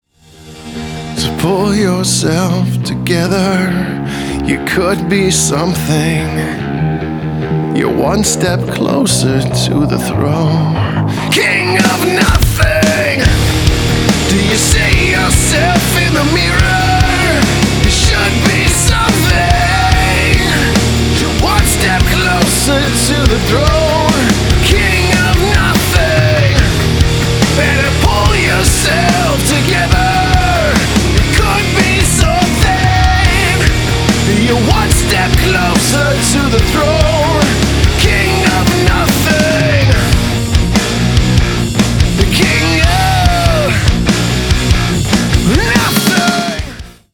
• Качество: 320, Stereo
мужской вокал
громкие
брутальные
Драйвовые
Alternative Metal
nu metal